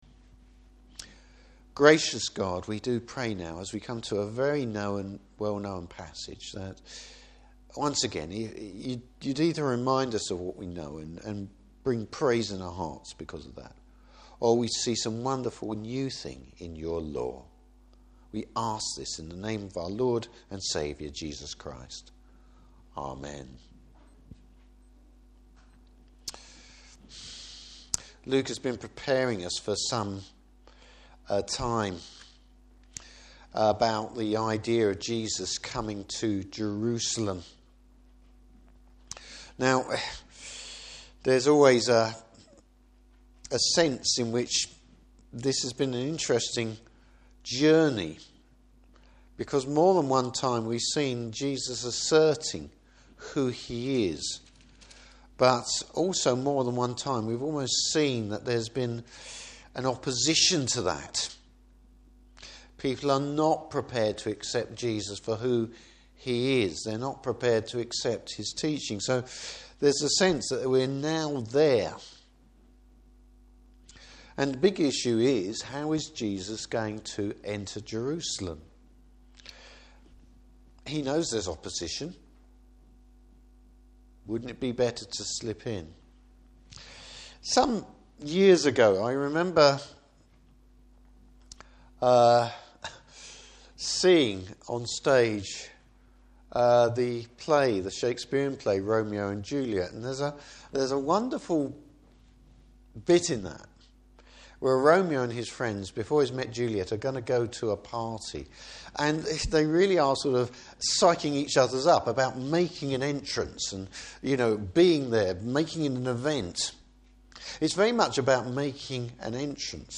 Service Type: Morning Service Bible Text: Luke 19:28-48.